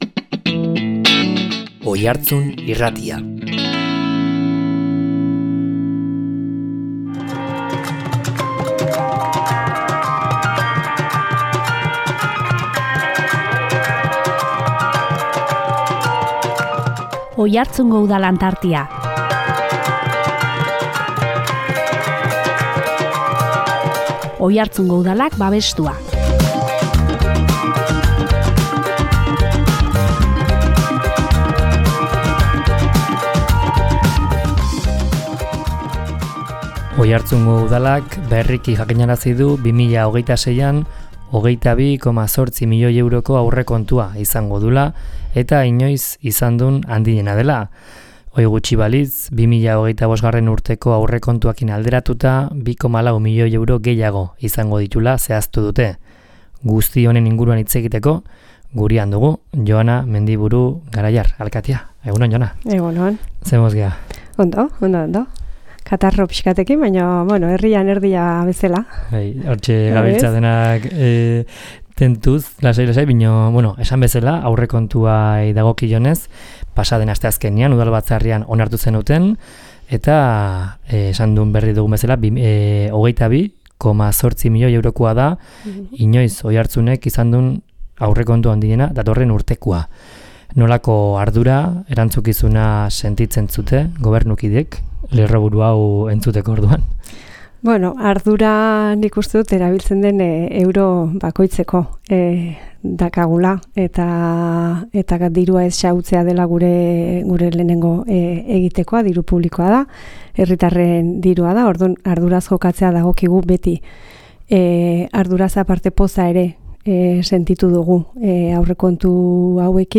Guzti honen inguruan hitz egiteko gurean izan dugu Joana Mendiburu Garaiar alkatea.